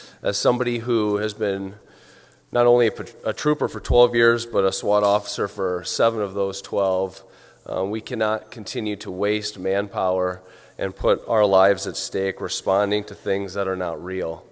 People who falsely report a shooting or other incident that would require a massive police response could be charged with a felony if the governor approves a bill that’s cleared the legislature. Representative Zach Dieken of Granville says during the last school year there were 89 of these false reports about non-existent threats in school districts.